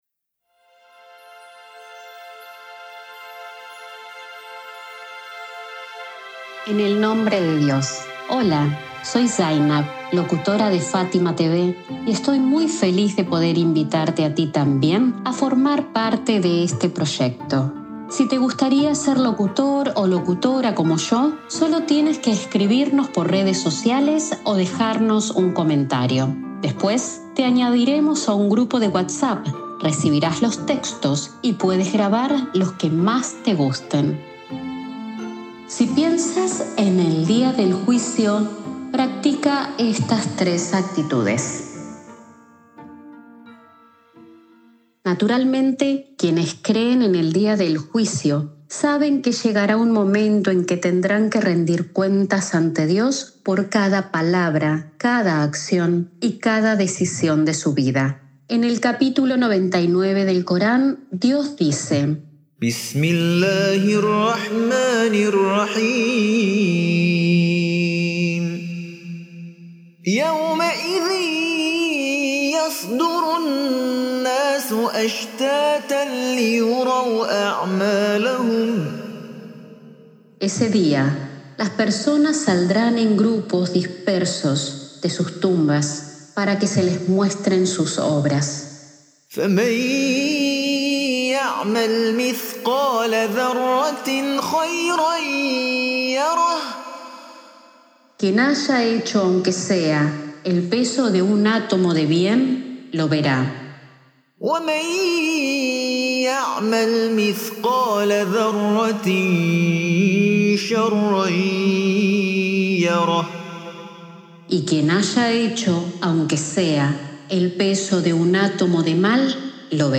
🎙 Locutora